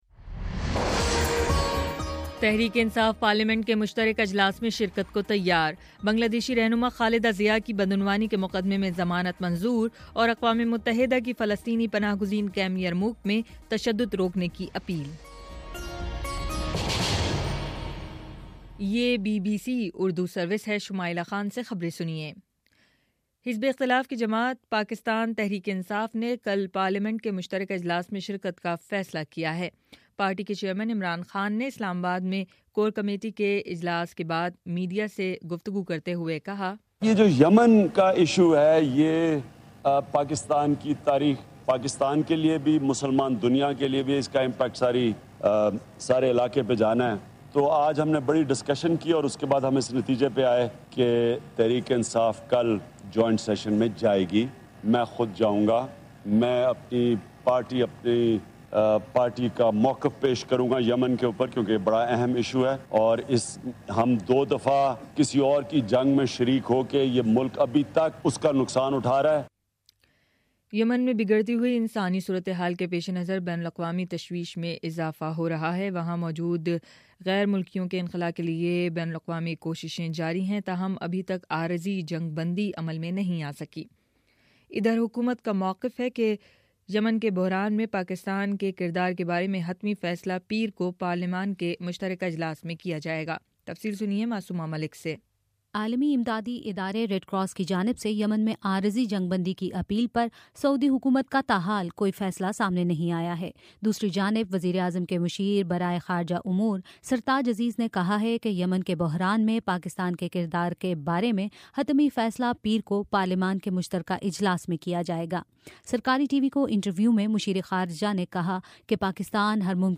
اپریل 5 : شام چھ بجے کا نیوز بُلیٹن